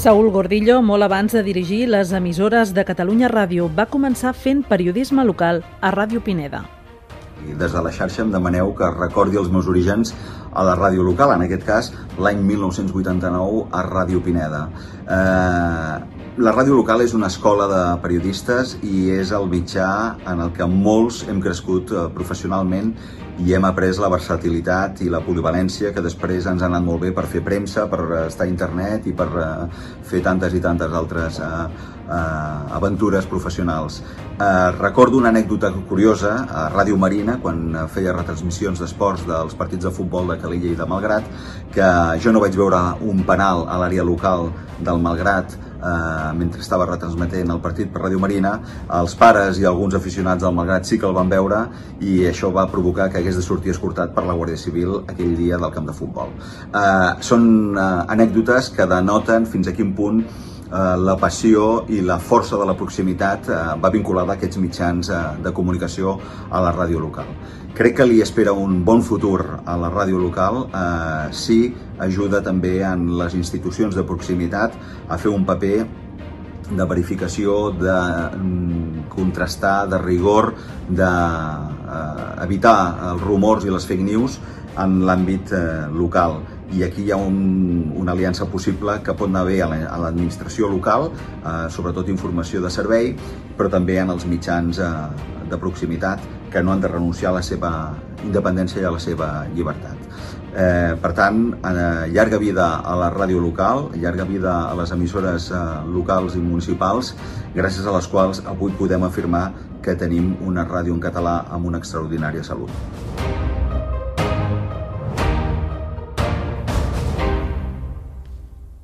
Ràdio Pineda
Divulgació